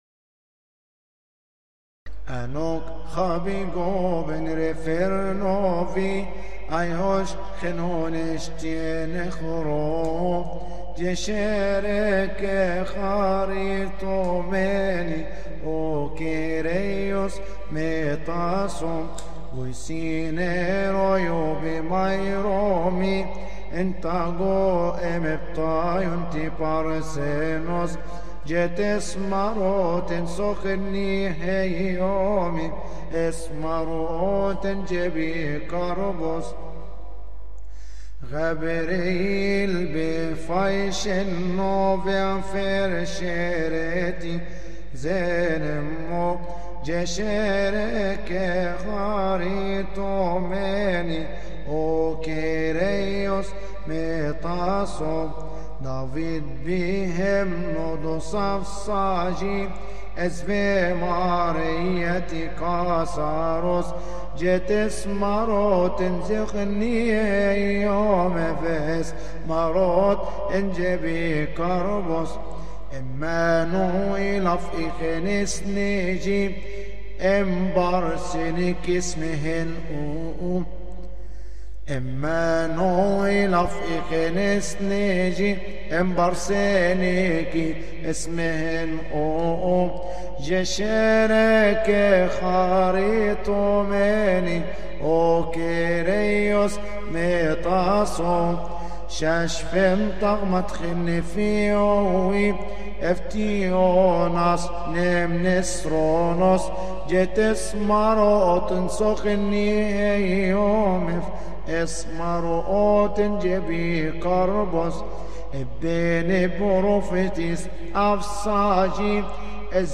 ابصالية واطس على ثيؤطوكية الجمعة تقال في تسبحة نصف الليل بشهر كيهك، قبطي.